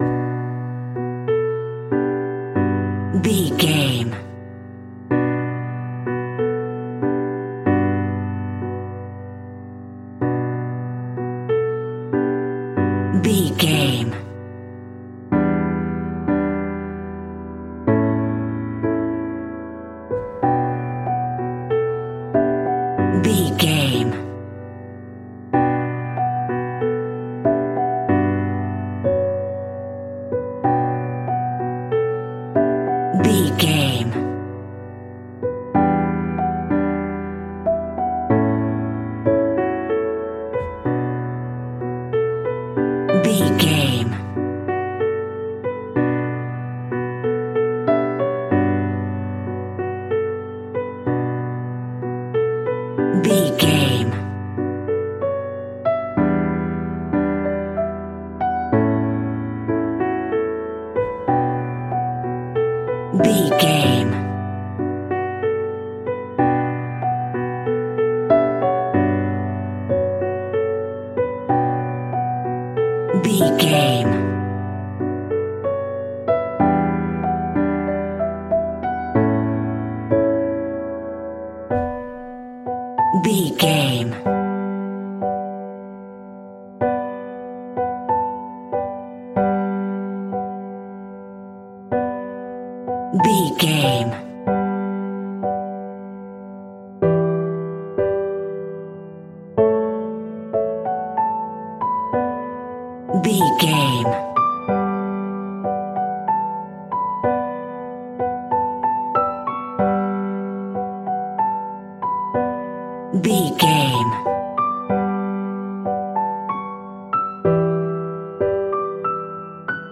Aeolian/Minor
Slow
tranquil
synthesiser
drum machine